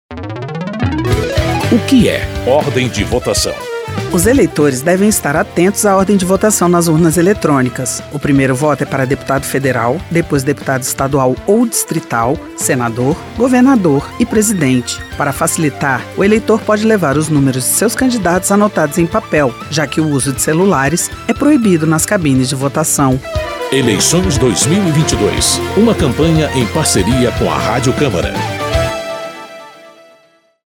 São 13 spots de 30 segundos.